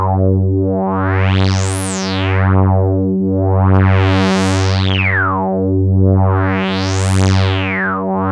MOOGFILTER.wav